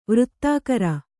♪ vřttākara